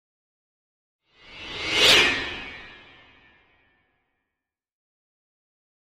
Piano Jarring Piano Hit, High Tones - Passing